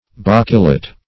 Search Result for " bockelet" : The Collaborative International Dictionary of English v.0.48: Bockelet \Bock"e*let\, n. (Zool.)